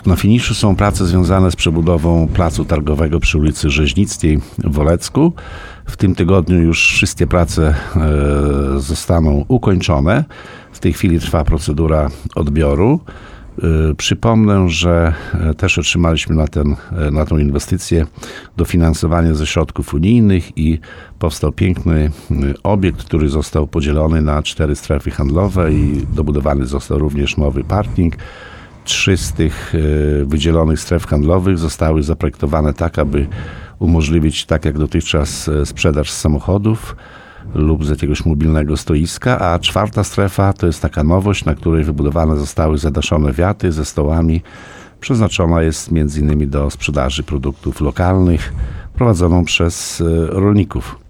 Mówi Wacław Olszewski, burmistrz Olecka.